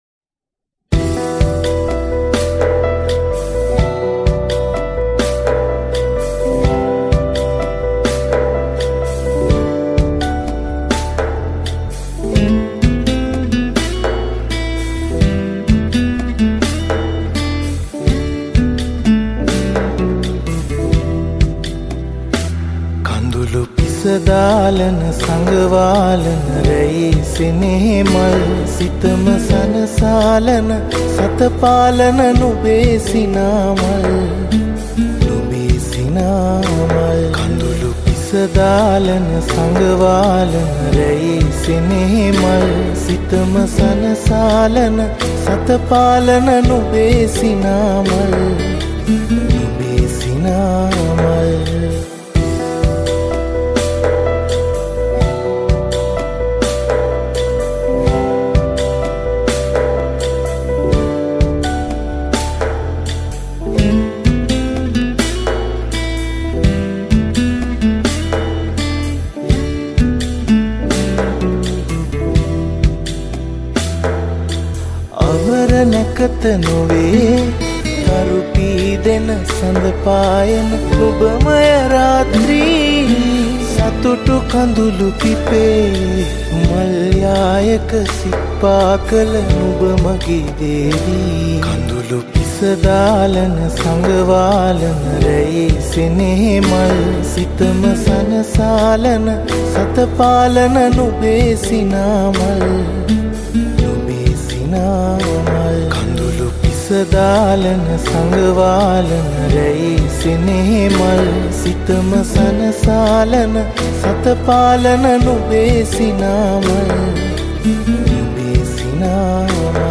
Lead guitar
Rythem guitar